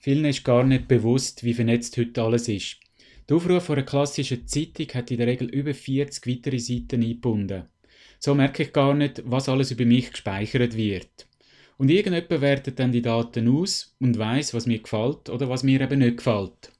Dieses Interview gibt es auch auf Hochdeutsch!!